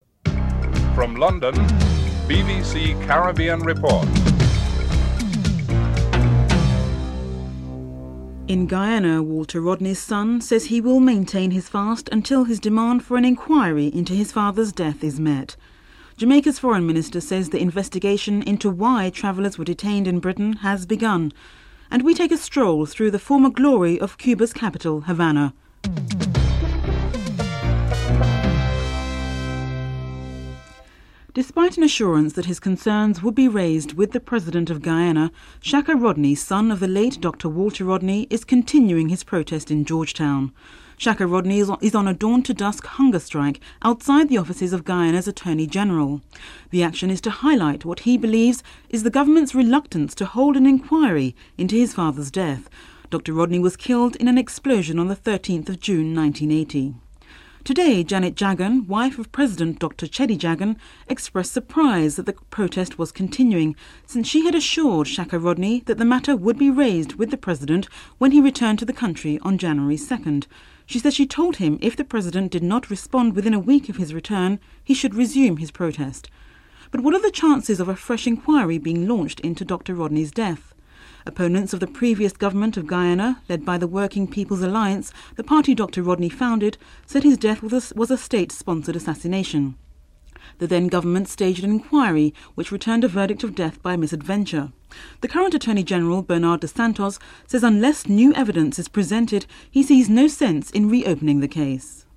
Jamaica’s Foreign Affairs Minister - Paul Robinson responds to the possible actions in response to the incident (06:14 - 08:18)